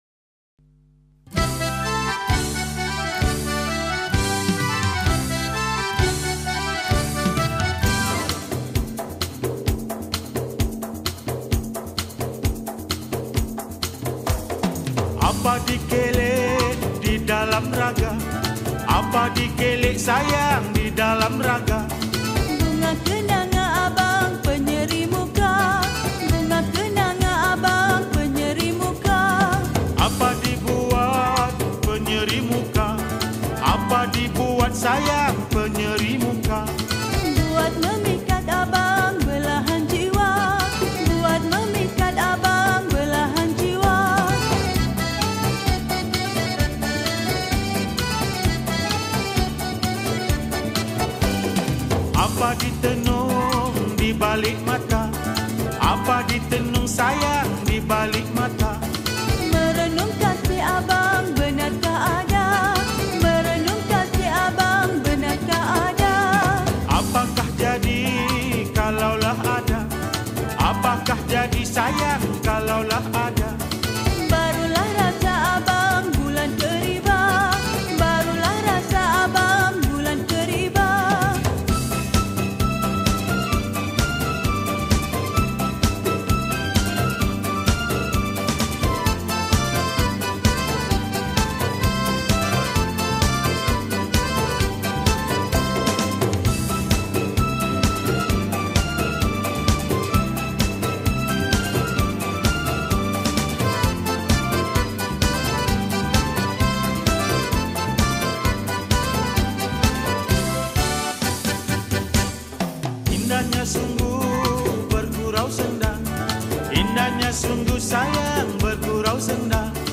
Indonesian Moluku Old Folk Song